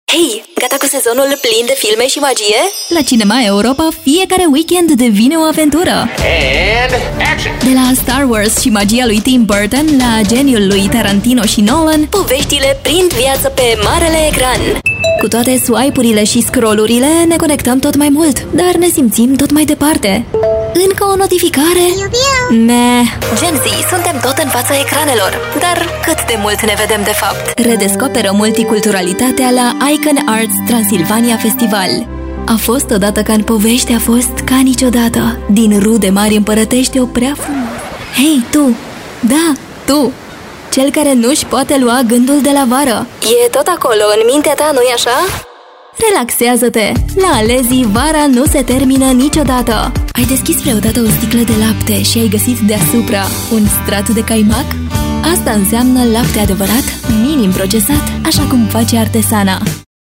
Passionate Energetic Happy Get my Quote Add to quote Invited Starting at $75